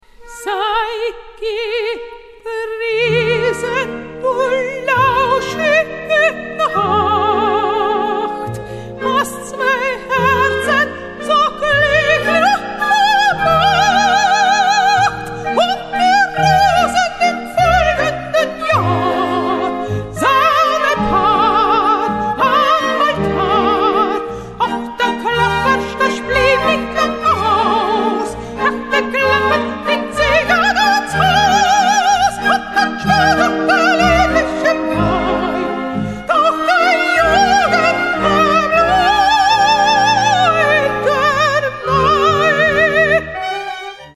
The CD contains both vocal and orchestral pieces.